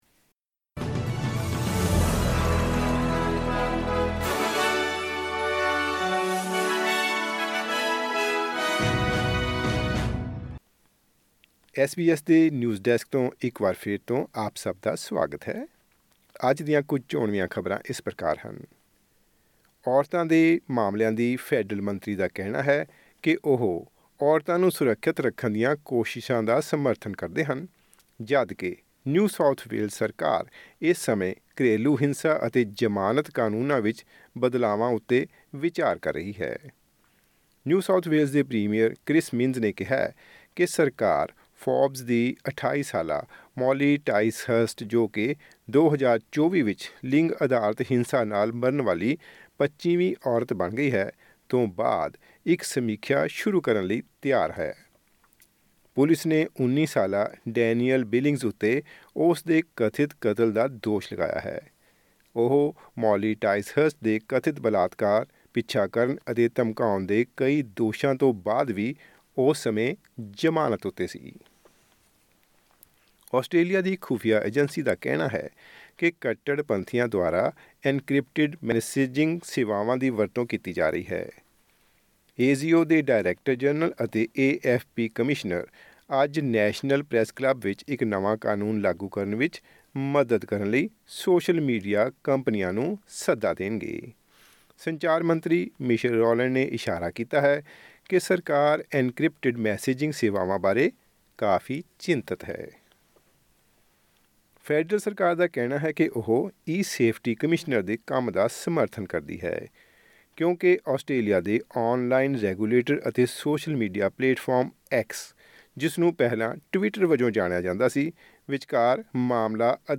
ਐਸ ਬੀ ਐਸ ਪੰਜਾਬੀ ਤੋਂ ਆਸਟ੍ਰੇਲੀਆ ਦੀਆਂ ਮੁੱਖ ਖ਼ਬਰਾਂ: 24 ਅਪ੍ਰੈਲ, 2024